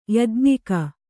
♪ yajñika